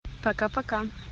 Звук расставания с девушкой